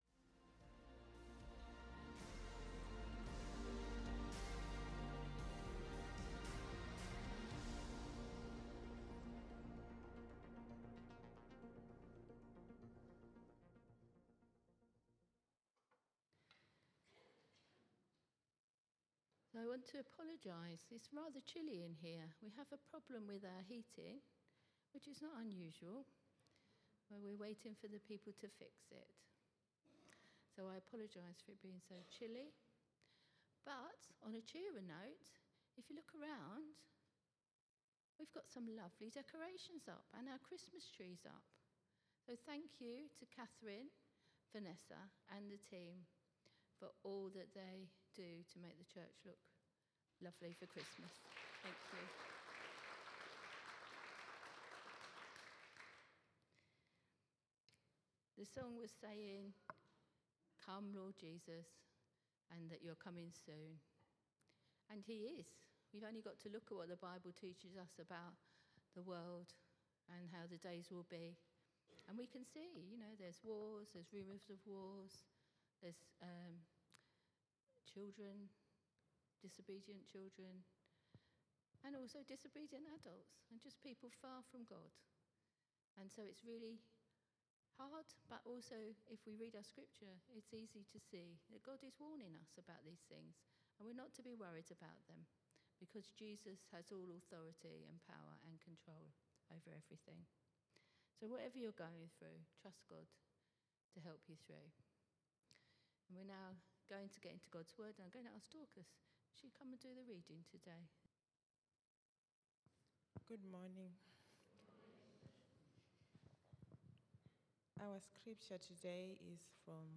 Recordings of our Sunday Services and other events.